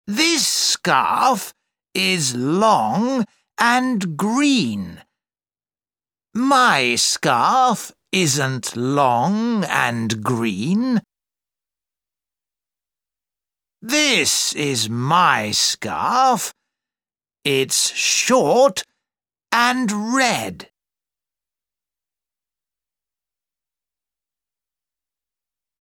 Track 3 Where's My Hat British English.mp3